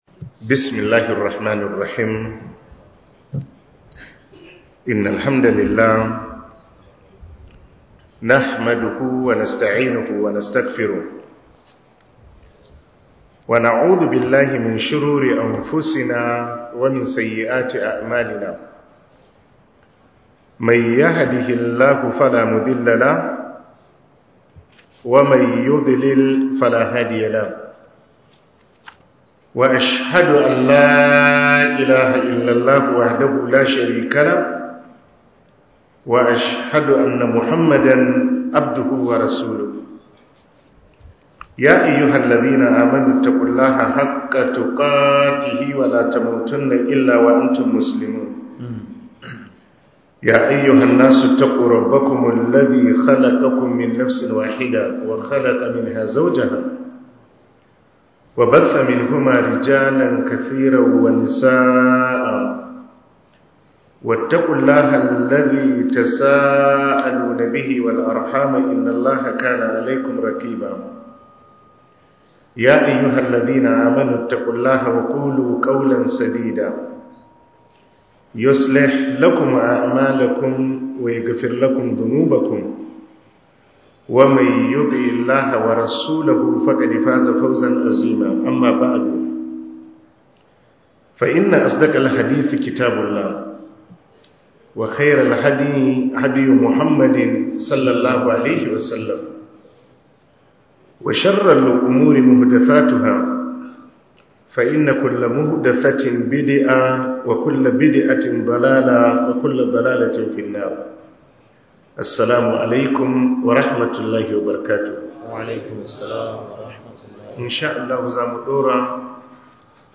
07 Ramadan Tafsir (Yamma) Copied!